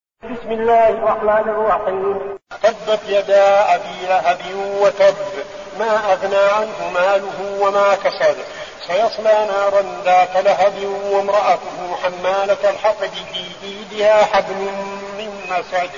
المكان: المسجد النبوي الشيخ: فضيلة الشيخ عبدالعزيز بن صالح فضيلة الشيخ عبدالعزيز بن صالح المسد